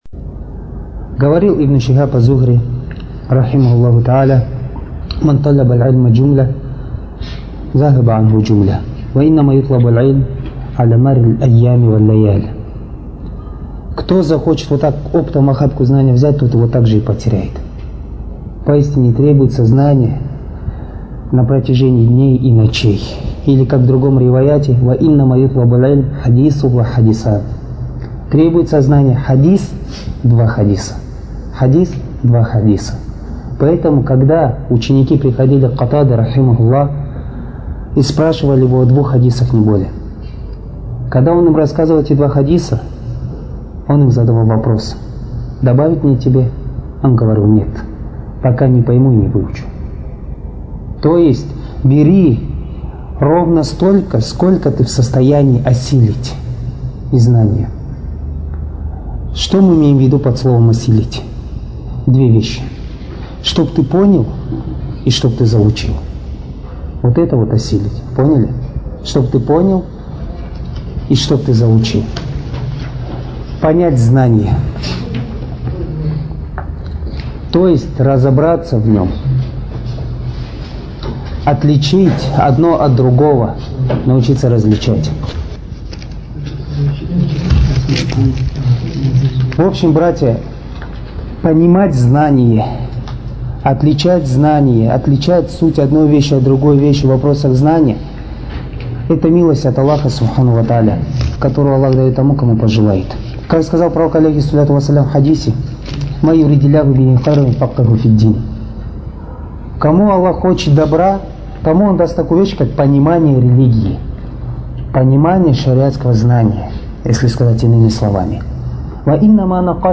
Материал:Лекция